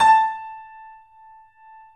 Index of /90_sSampleCDs/E-MU Producer Series Vol. 5 – 3-D Audio Collection/3D Pianos/YamaHardVF04